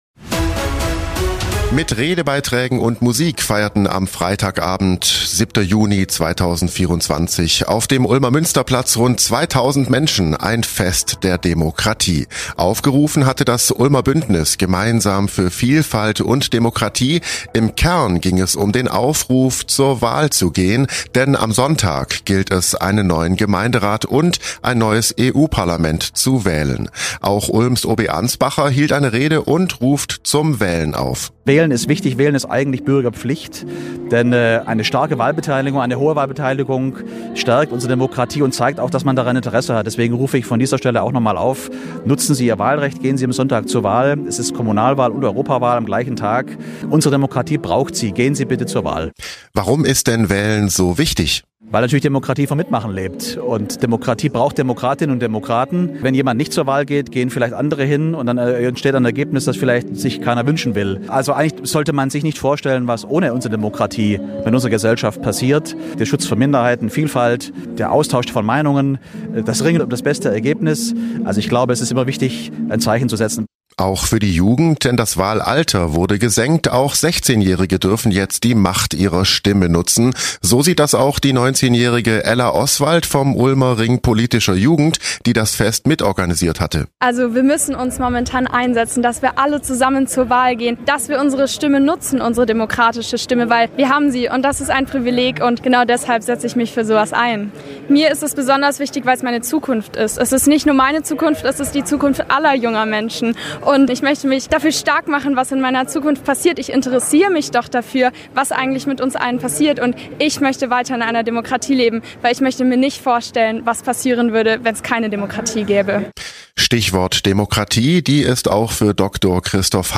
„Fest der Demokratie“ - Großer Wahlaufruf vom Ulmer Münsterplatz
Nachrichten
Auch Ulms OB Ansbacher hielt eine Rede und